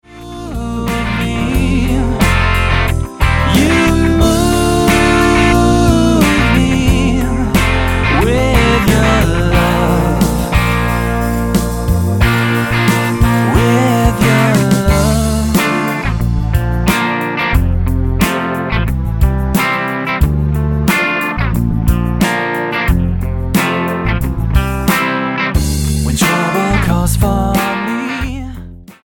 STYLE: Rock
The overall mix is pleasant on the ear and well balanced.